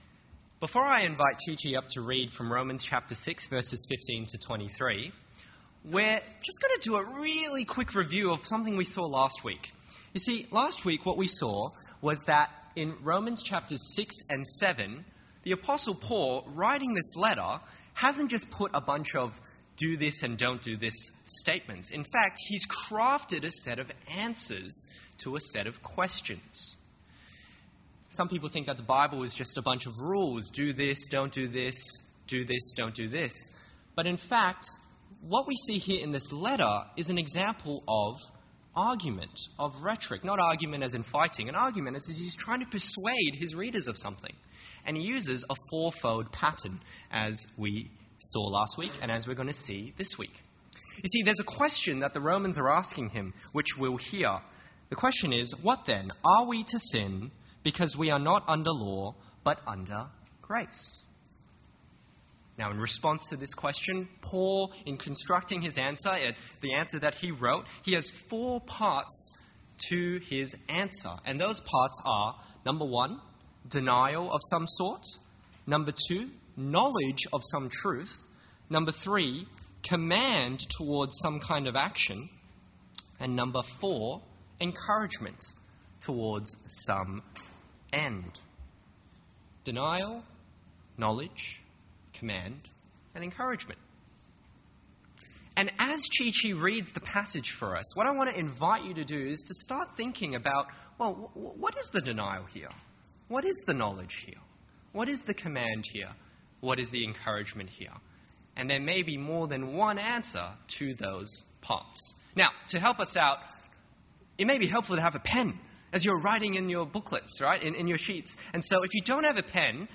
An edited audio version of Week 4, Term 2 of Forums 2024. Forum is a 2 hour session of interactive Bible teaching for 18-30 year olds.